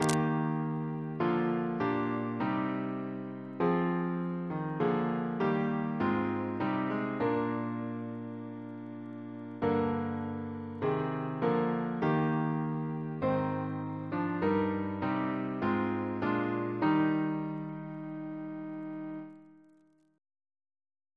Double chant in E♭ Composer: Sir George Elvey (1816-1893), Organist of St. George's Windsor; Stephen's brother Reference psalters: ACB: 30; OCB: 48; RSCM: 49